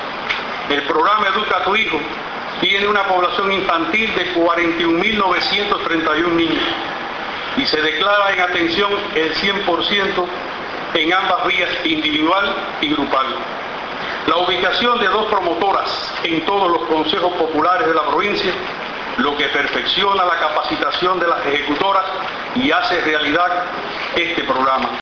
En la apertura del seminario preparatorio, en Granma, del venidero período lectivo 2017-2018 realizado en Bayamo durante los días 24 y 25 de mayo, Leonardo Manuel Tamayo Vázquez, director provincial de Educación, resaltó que el programa Educa a tu Hijo tiene una población de 41 mil 931 niños, declarada atendida en su totalidad.
Palabras de Manuel Leonardo Tamayo Vázquez